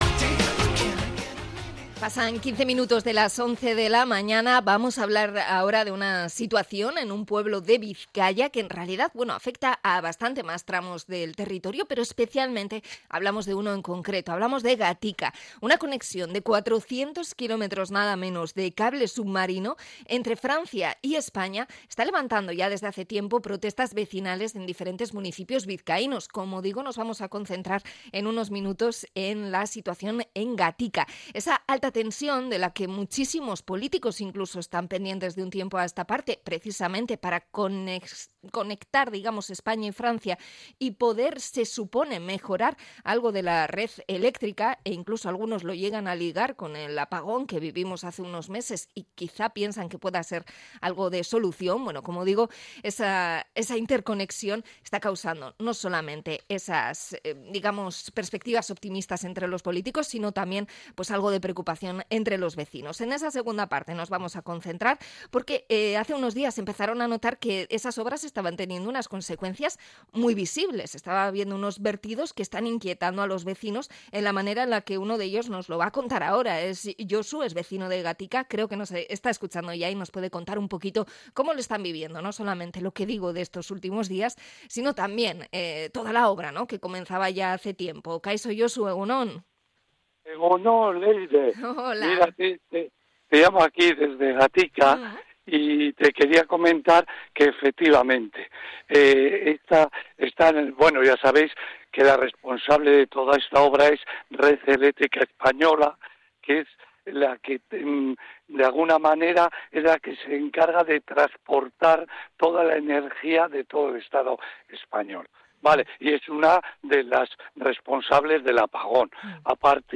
Hablamos con los vecinos de Gatika sobre las obras de la interconexión eléctrica